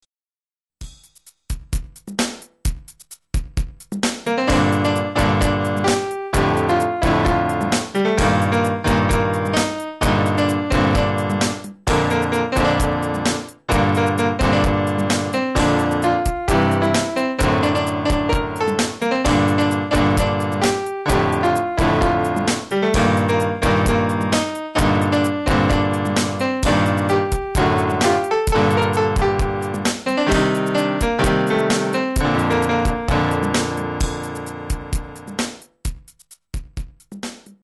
買ってからあまり使ってないキーボード、最近たまに触ってます。
某所の楽譜PDFを印刷してやってますが、そのままじゃ弾けないんで簡略化して練習してます。
今日と金曜の合わせて２～３時間ほど練習して、ようやくAメロ覚えました。